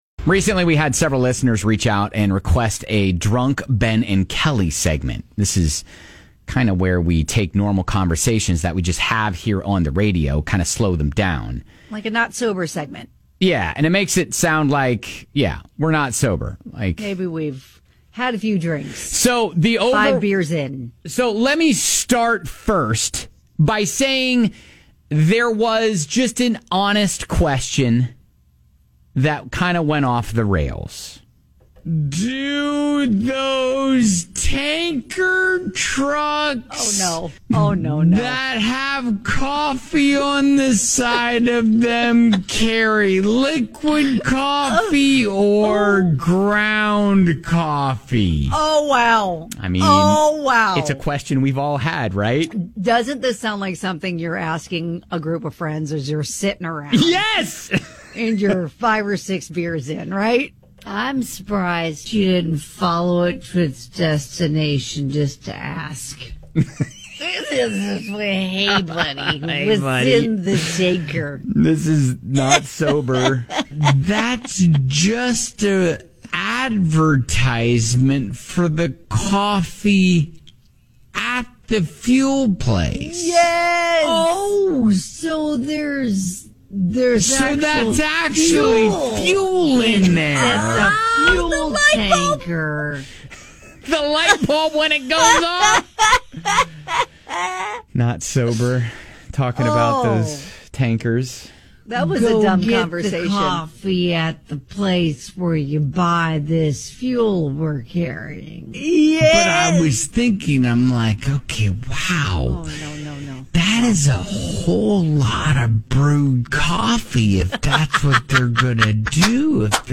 This is where we take normal conversations we have here on the radio and slow them down. The result makes us sound like we’re “not sober”!